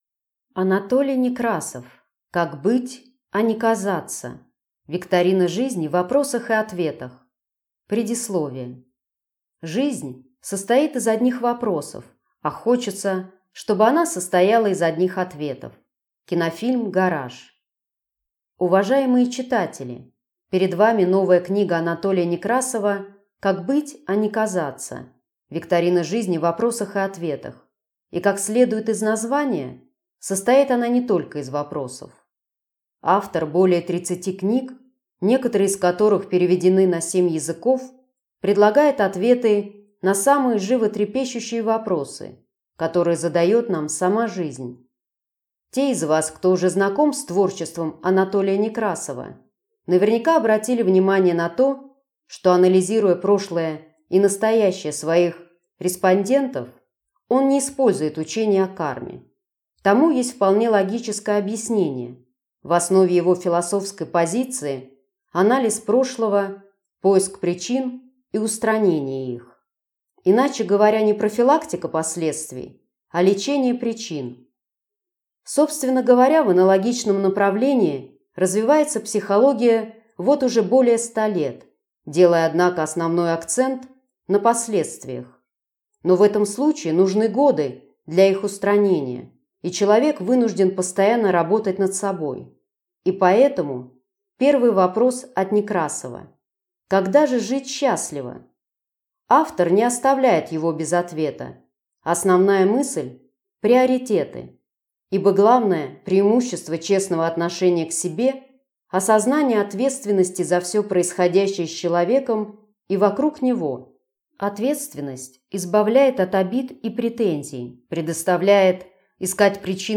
Аудиокнига Как быть, а не казаться. Викторина жизни в вопросах и ответах | Библиотека аудиокниг